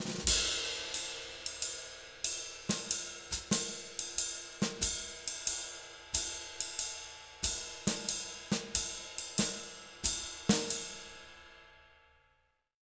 92SWING 03-L.wav